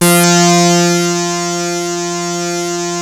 OSCAR F4 2.wav